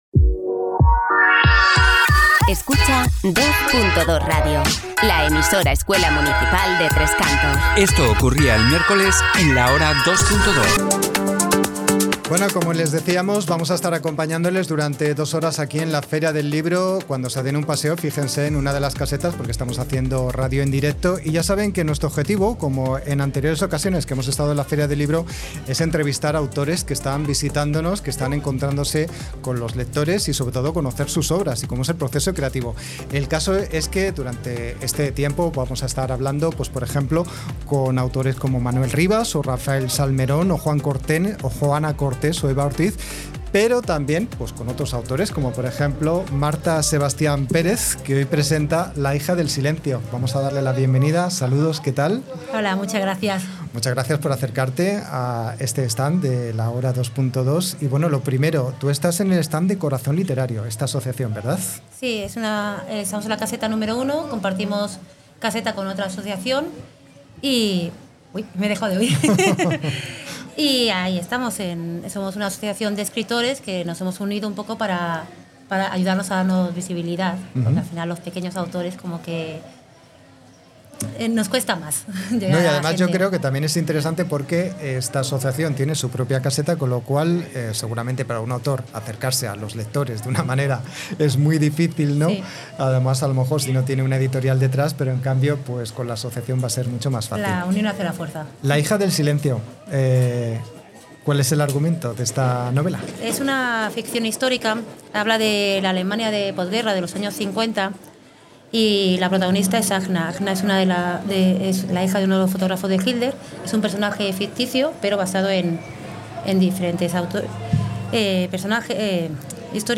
Un año más el magazine La Hora 2.2 de la radio escuela Dos. Dos de Tres Cantos hace un programa especial desde la Feria del Libro de la localidad que tiene lugar del 8 al 11 de mayo en la Avenida de Colmenar Viejo.